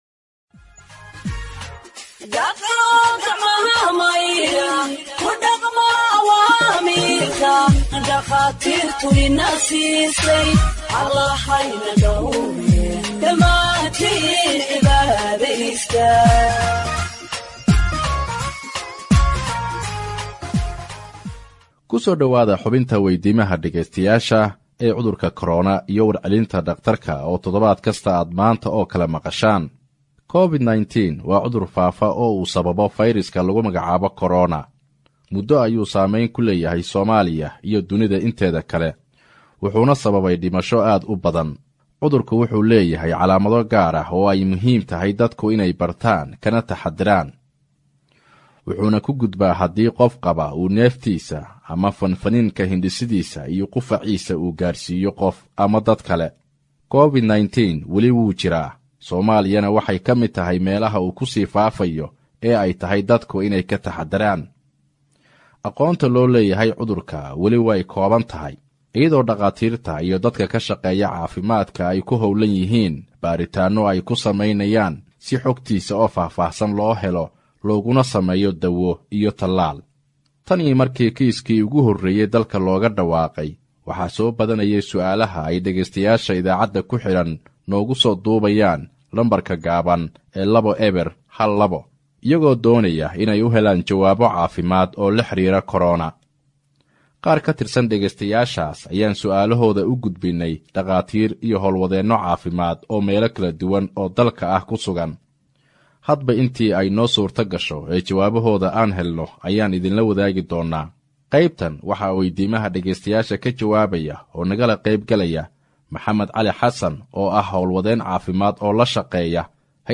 HEALTH EXPERT ANSWERS LISTENERS’ QUESTIONS ON COVID 19 (60)